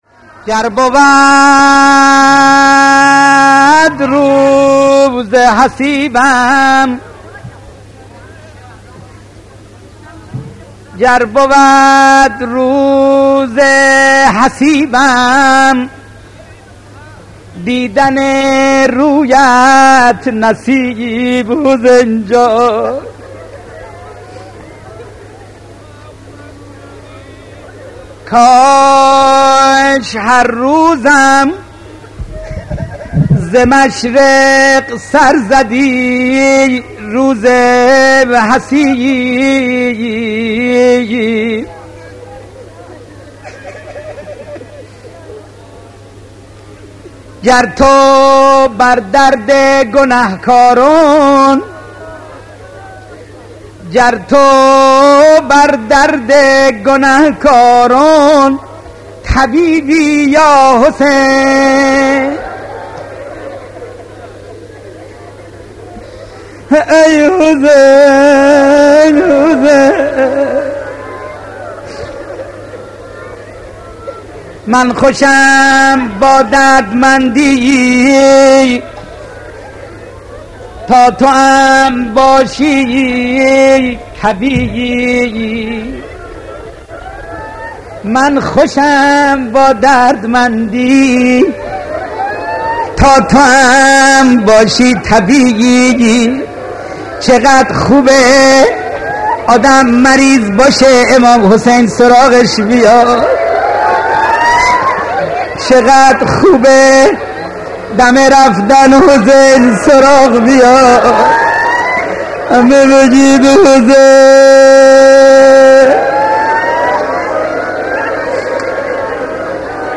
مداح اهل بیت استاد